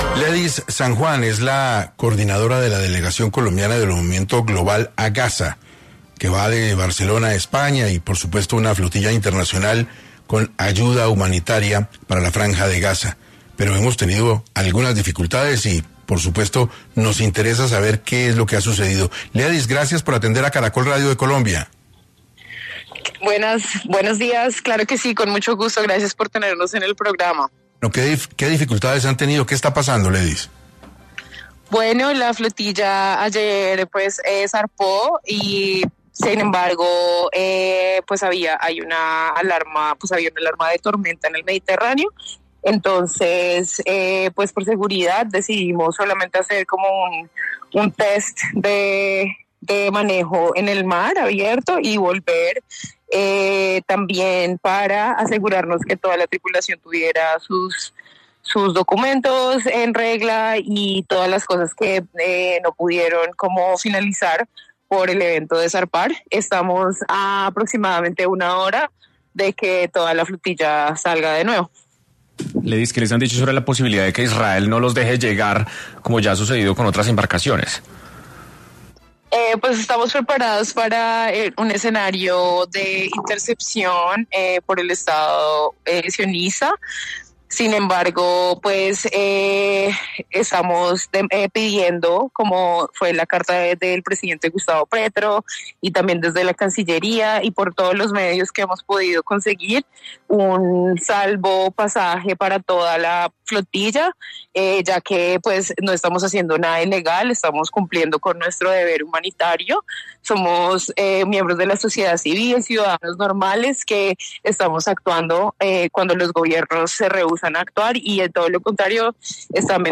Radio en vivo